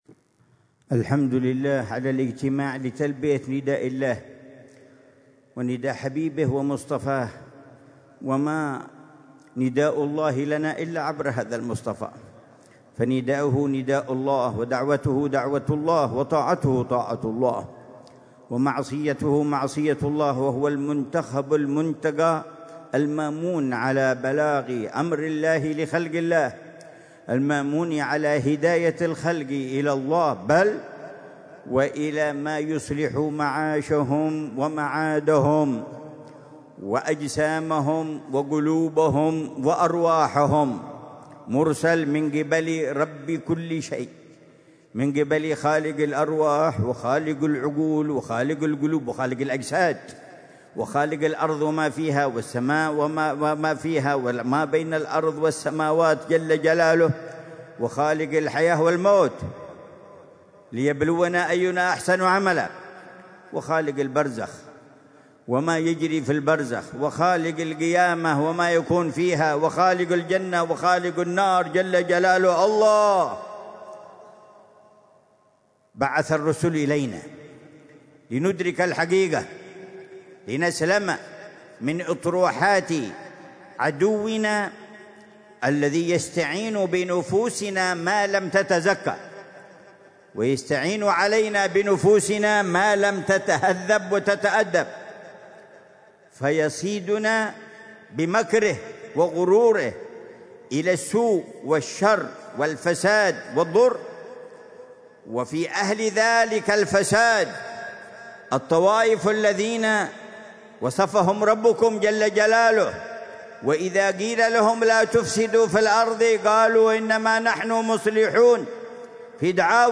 محاضرة العلامة الحبيب عمر بن محمد بن حفيظ في المولد السنوي في جامع كلية الشريعة بجامعة الأحقاف، ليلة الخميس 26 ربيع الأول 1447هـ بعنوان: